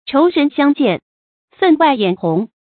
chóu rén xiāng jiàn，fèn wài yǎn hóng
仇人相见，分外眼红发音